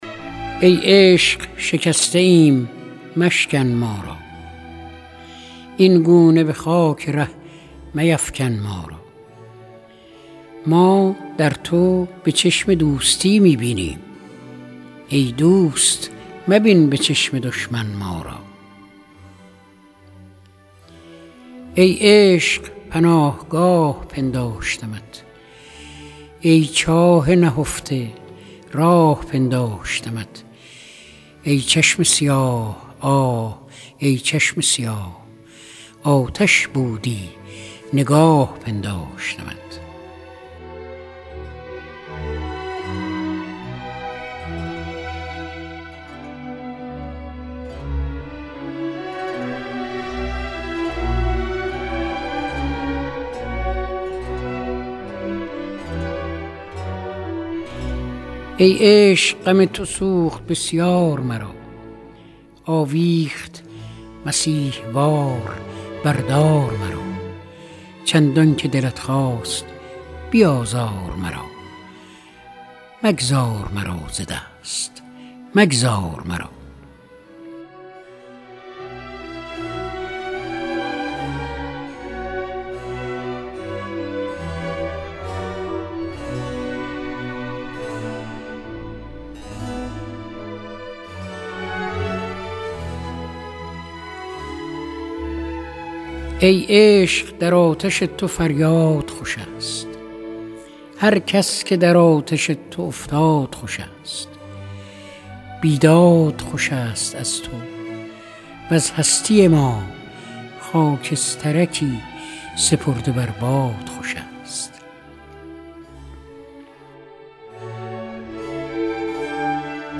دانلود دکلمه فریدون مشیری بنام ای عشق
گوینده :   [فریدون مشیری]